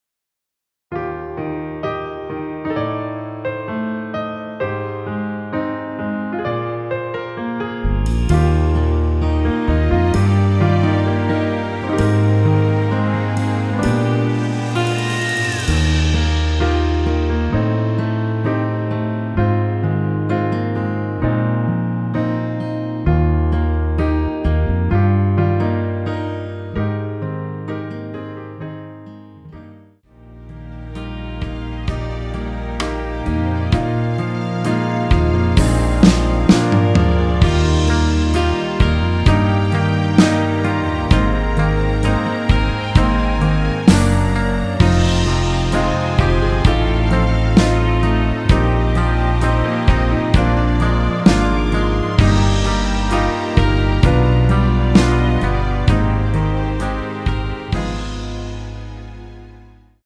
Eb
앞부분30초, 뒷부분30초씩 편집해서 올려 드리고 있습니다.
중간에 음이 끈어지고 다시 나오는 이유는